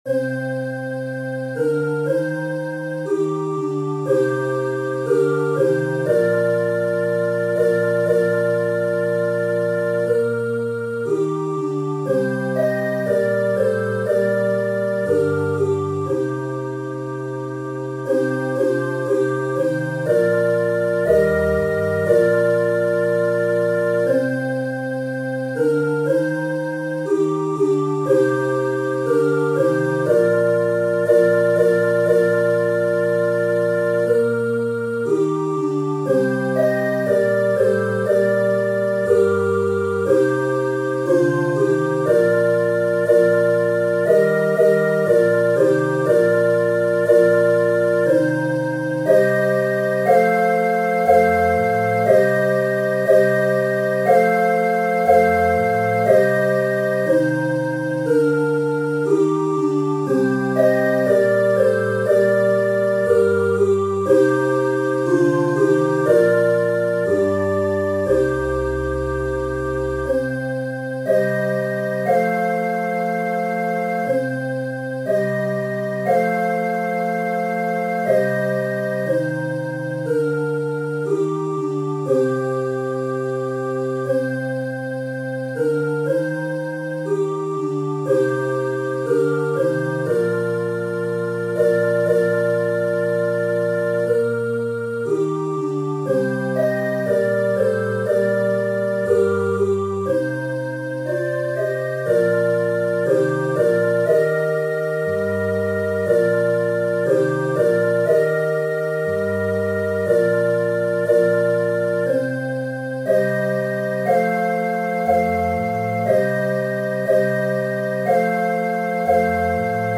При этом нота произвольной длительности (|о|) (чаще всего) звучит как нота двойной длительности (breve).
Выбор инструментов для электронного озвучивания партитуры никакого духовного или художественного смысла не несет, а отражает субъективные предпочтения наборщика.
Милость мира (дорийская) 09.29.2023